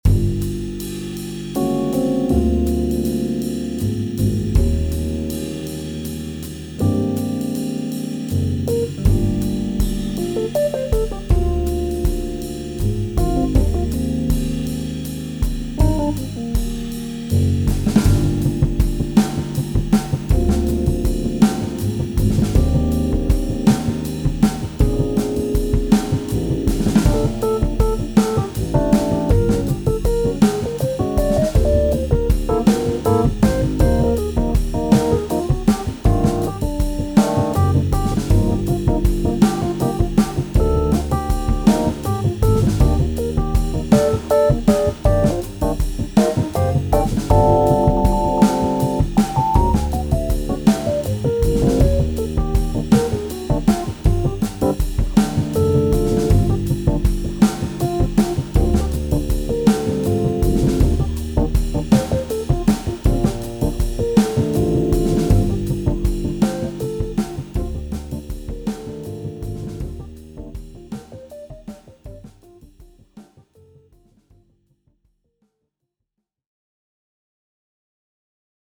Here’s a trio that does not exist, conjured from the immense sound library that is Native Instruments’ Komplete. I programmed the drum track and performed the Rhodes and bass tracks live.
jazz trio